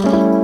smoothpiano.wav